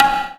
normal-hitwhistle.wav